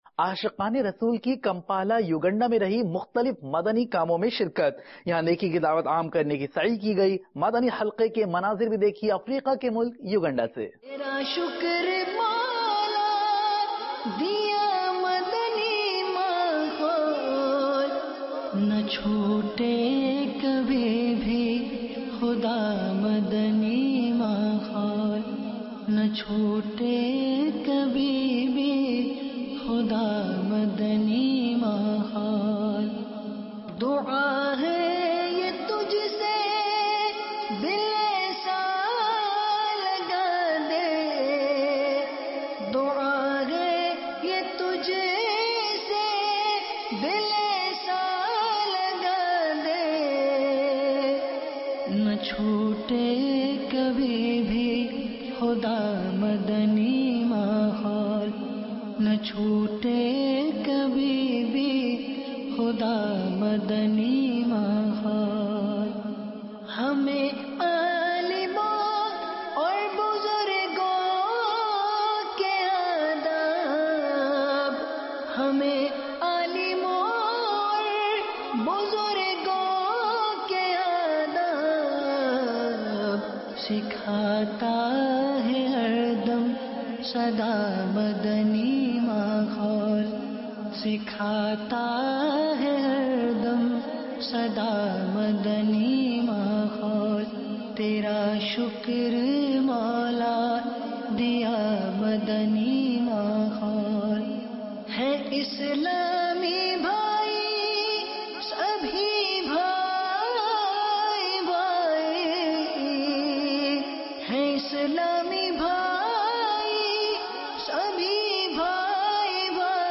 News Clip-28 Jan – Ashiqan-e-Rasoolﷺ Kay South Africa Main Mukhtalif Madani Kaam